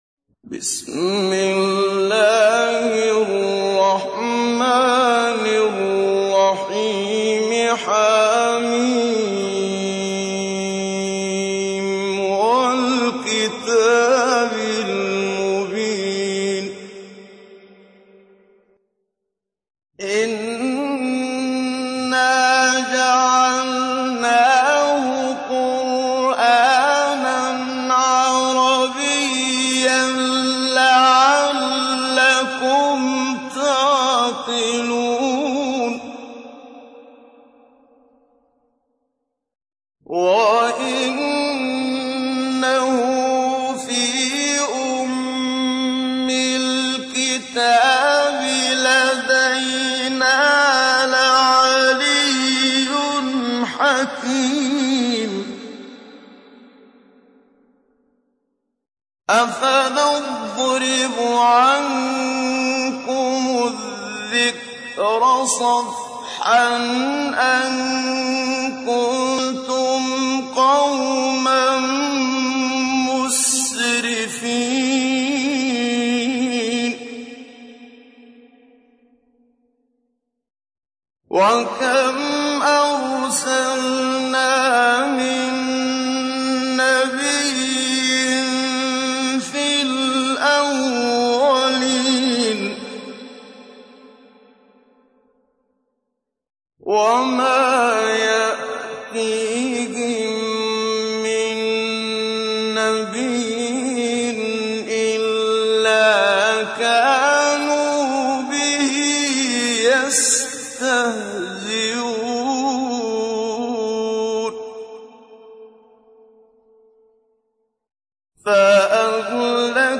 تحميل : 43. سورة الزخرف / القارئ محمد صديق المنشاوي / القرآن الكريم / موقع يا حسين